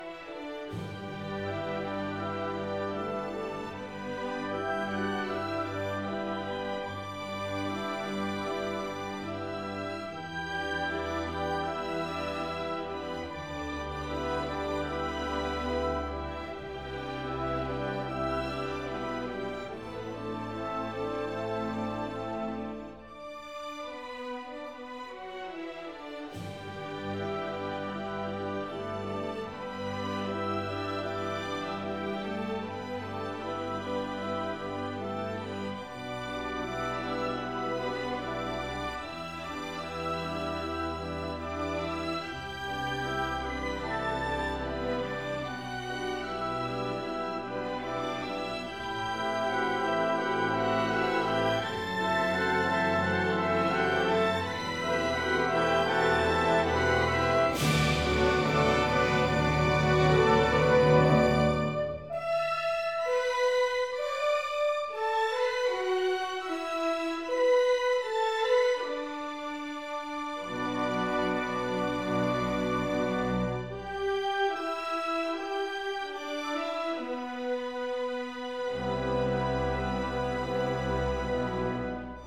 小提琴
中國音樂、發燒音樂